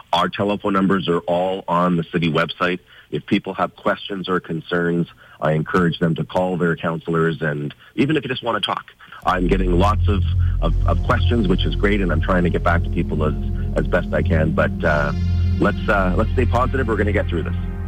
Speaking with the Mix Morning Crew, he said they’re also trying to ease burdens in other ways.